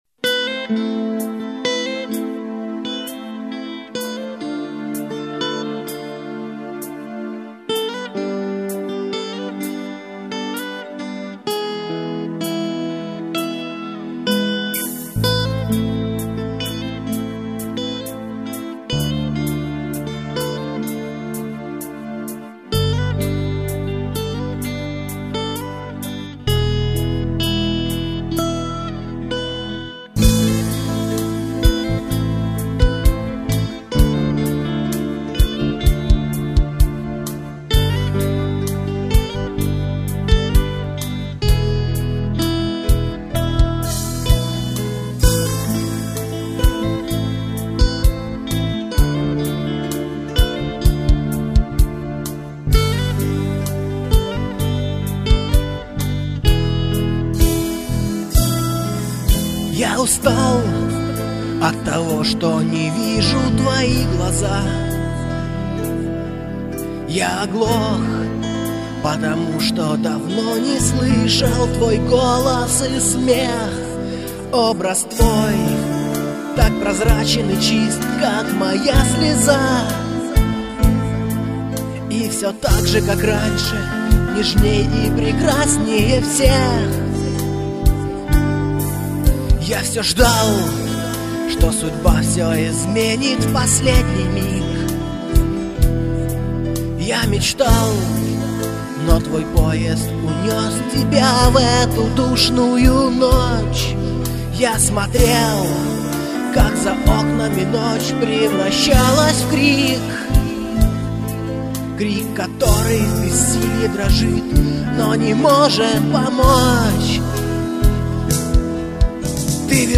Гитары, клавиши, перкуссия, вокал